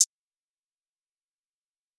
Murda hi hat.wav